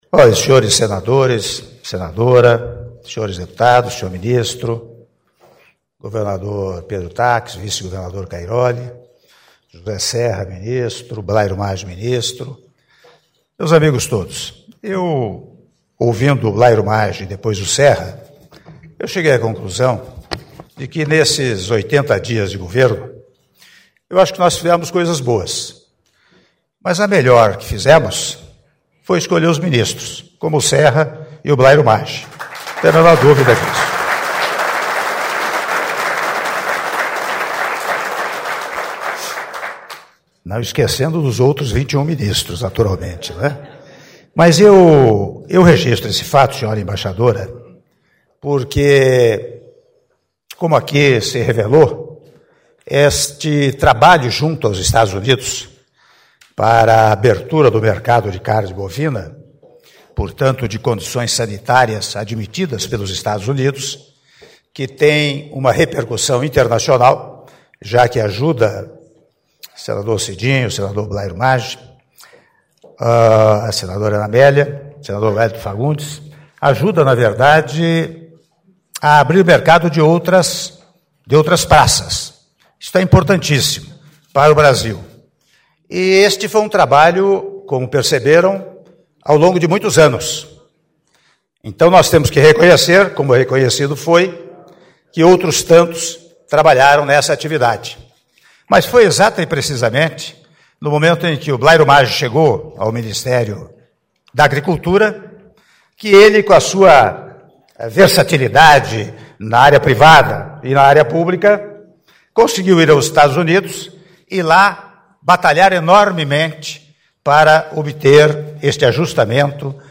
Áudio do discurso do Senhor Presidente da República em exercício, Michel Temer, durante cerimônia de troca de Cartas de Reconhecimento de Equivalência dos Controles Oficiais de Carne Bovina entre o Brasil e o EUA- Brasília/DF (05min56s)